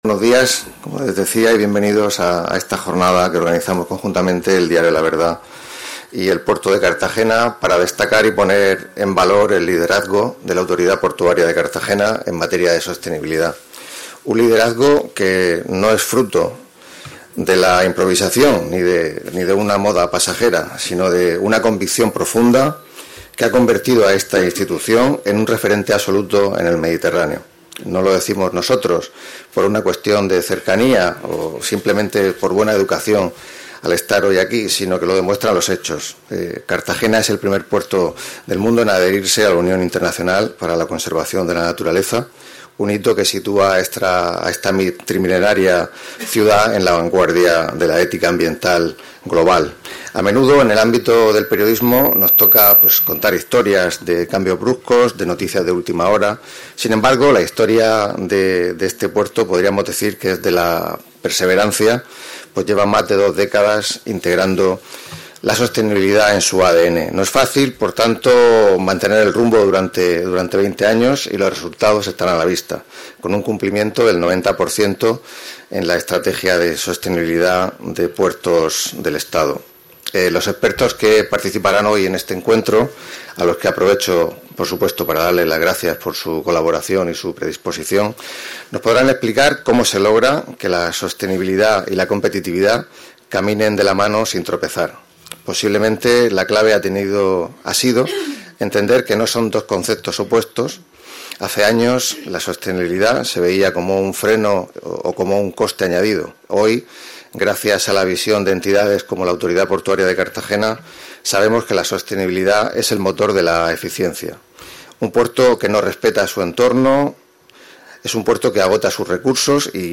La primera edil ha participado este jueves en una jornada sobre sostenibilidad portuaria que ha contado con expertos ambientales y representantes del sector